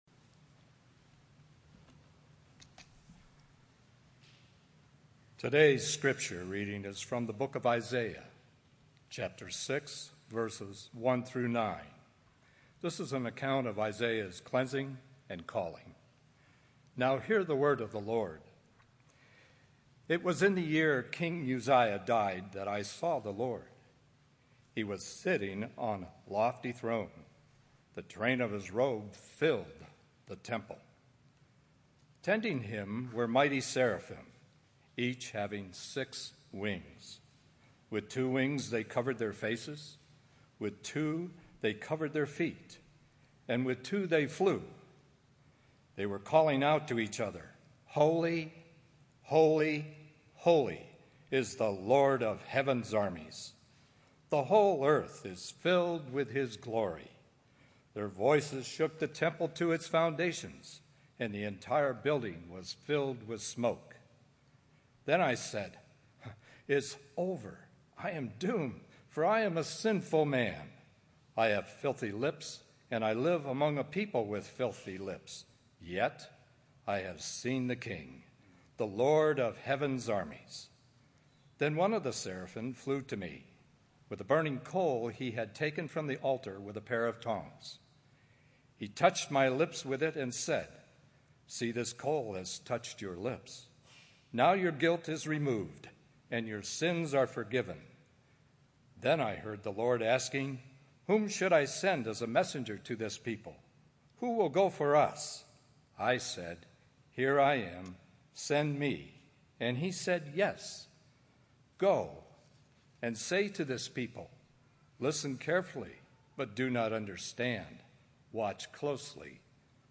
The 2015 summer sermon series bring to you messages that focus on some of the essential elements of what it means to be the body of Christ.